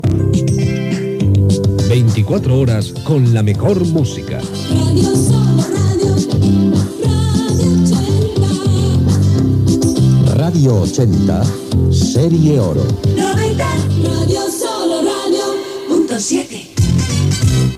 Indicatiu amb la freqüència dels 90.7 MHz de Barcelona.
FM